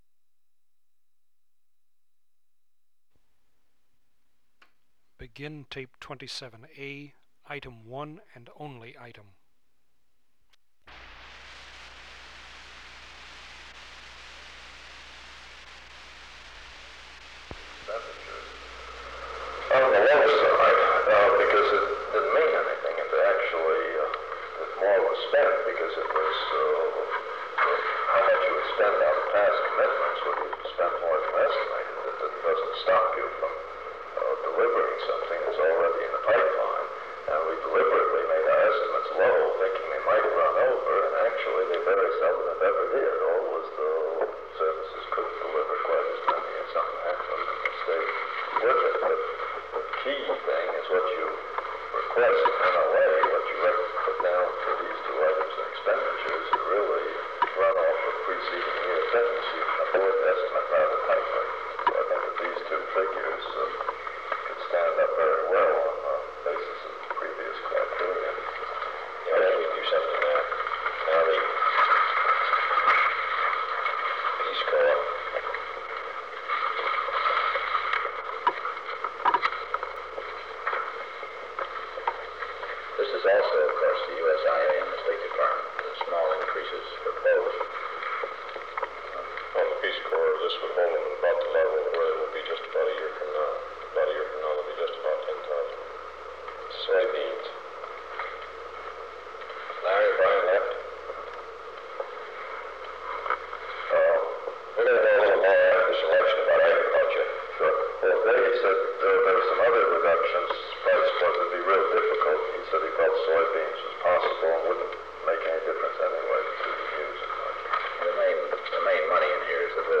Meeting on the Budget
Secret White House Tapes | John F. Kennedy Presidency Meeting on the Budget Rewind 10 seconds Play/Pause Fast-forward 10 seconds 0:00 Download audio Previous Meetings: Tape 121/A57.